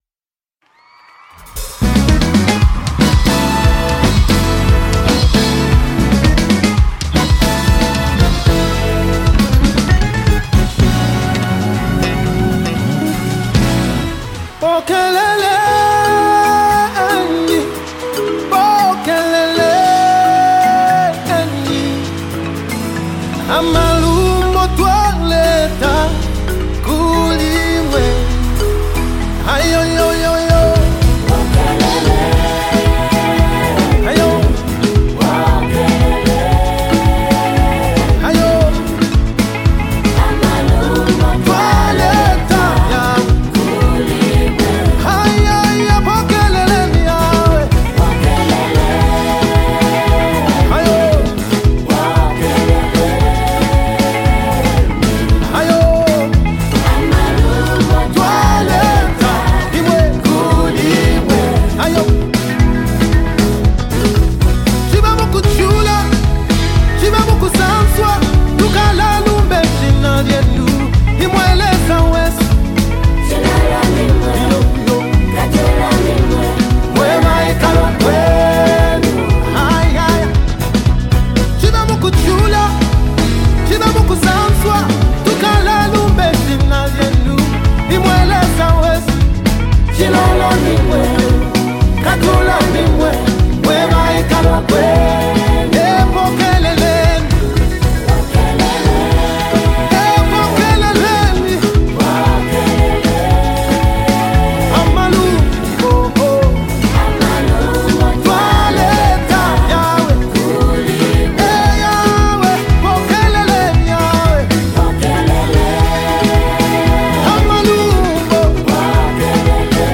Gospel Music
energetic Afrobeat